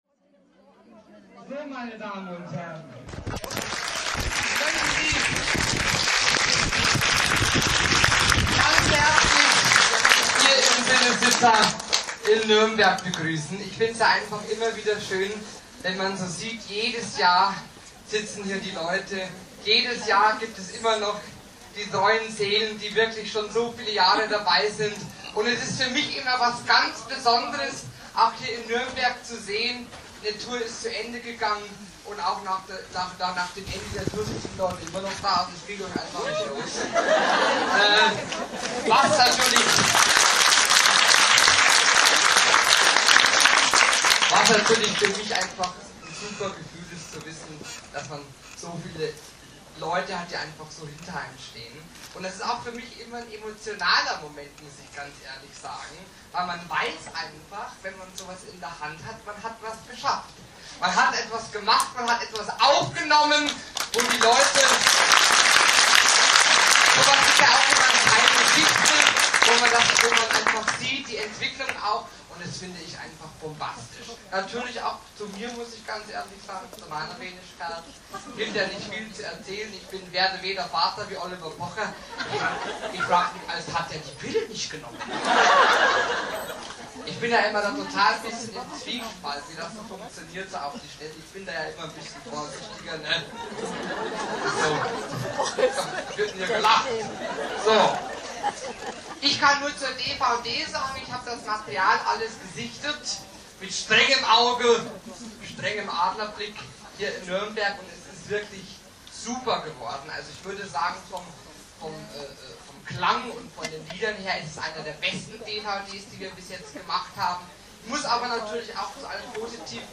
Anmoderation